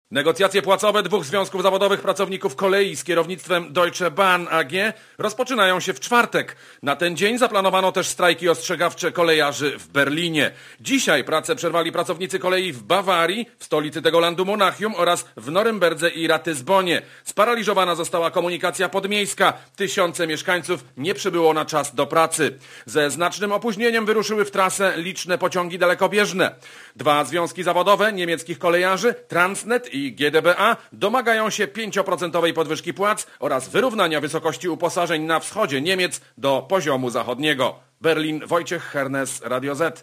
Korespondencja z Niemiec (312Kb)